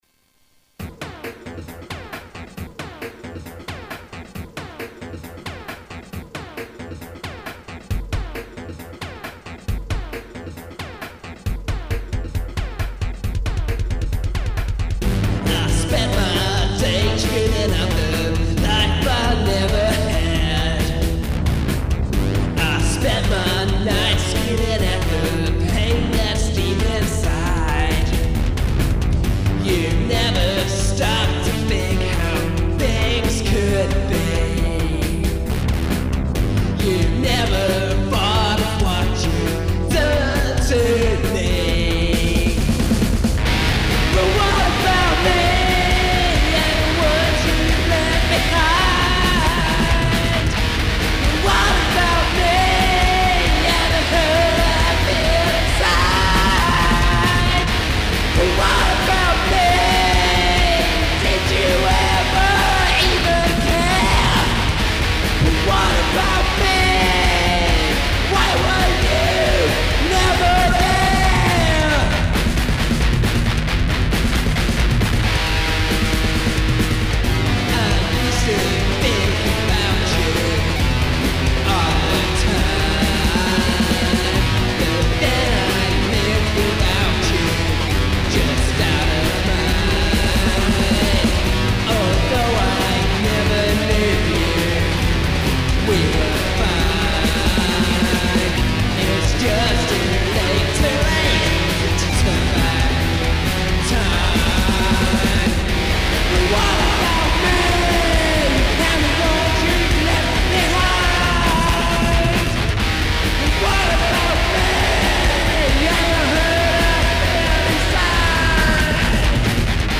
devon demos - audition a local band
Devon Demos - Guitar
Satyrical, Emotive, Noise!